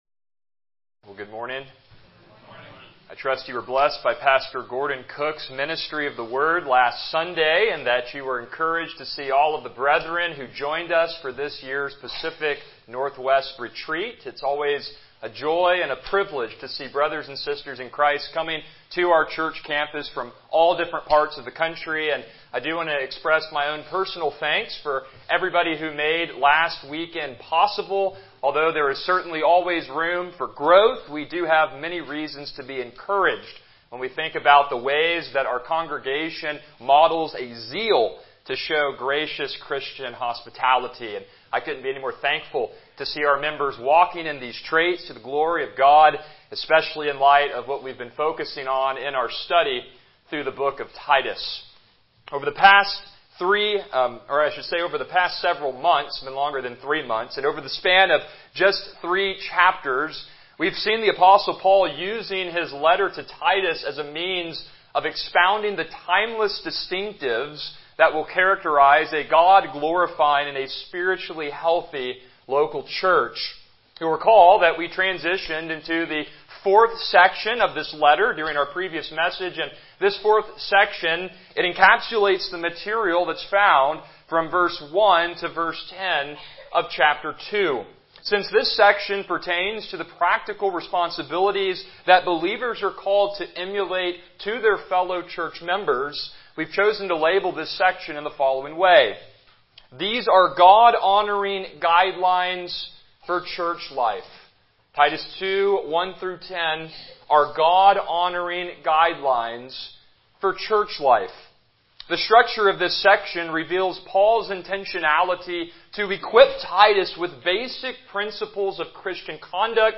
Passage: Titus 2:3-4 Service Type: Morning Worship